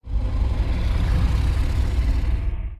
Sfx_creature_iceworm_idle_exhale_02.ogg